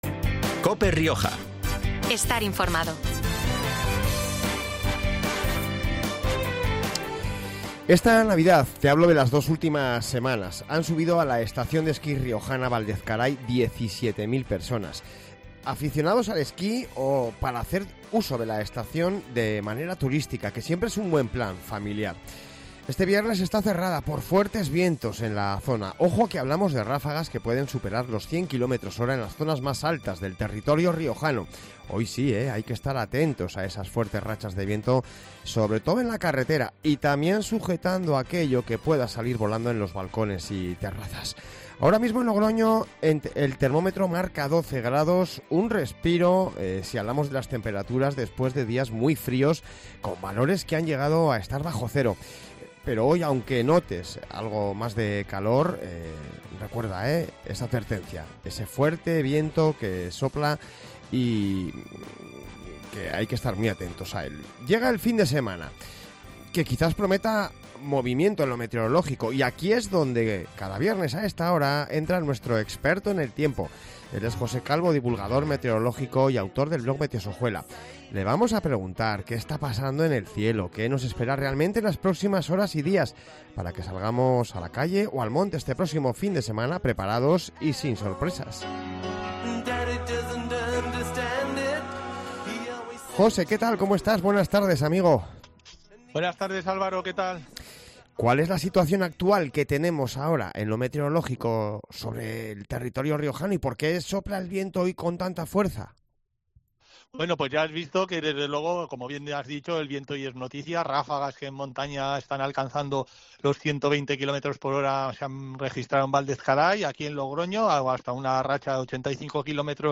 Divulgador Meteorológica